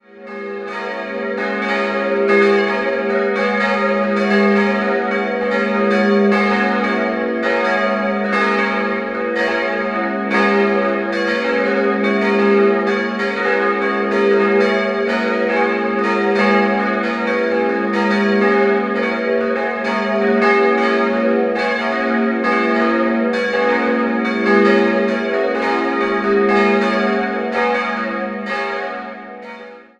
4-stimmiges Geläut: as'-b'-des''-es'' D ie große Glocke wurde 1977, die beiden mittleren 1974 von der Gießerei Bachert in Kochendorf gegossen. Die kleine stammt noch aus dem Vorgängerbau und stammt aus dem 16. Jahrhundert.